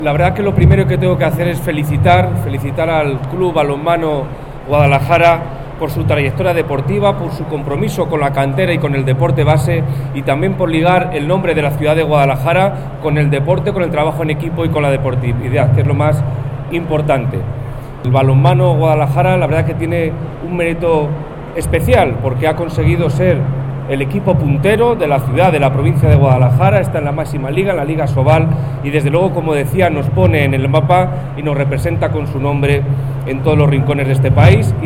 El delegado de la Junta en Guadalajara, Alberto Rojo, felicita al Balonmano Guadalajara por su trayectoria y por sus méritos deportivos y sociales.